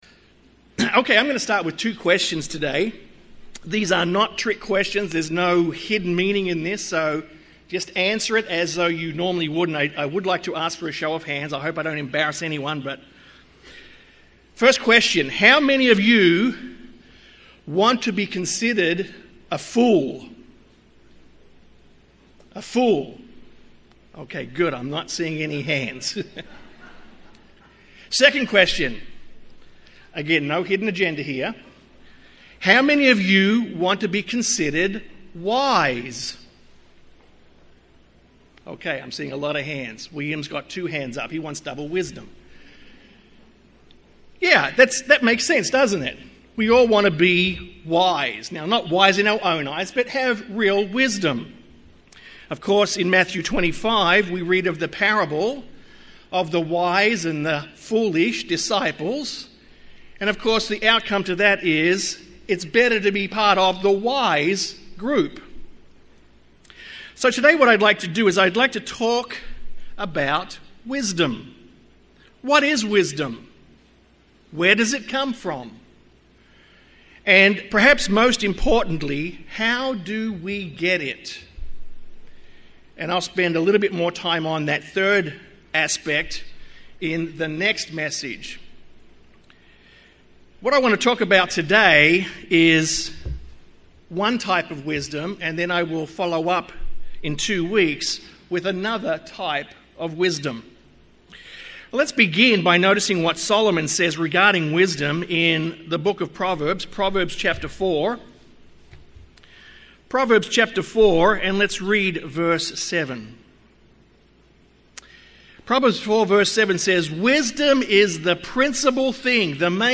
In Proverbs 4:7 we are told to "get wisdom", but Isiah 3:13-17 explains that there are two different types of wisdom, the wisdom of man and the wisdom of God. This sermon begins a discussion on wisdom by looking at the wisdom of man and how as Christians, we need to be on guard against such wisdom.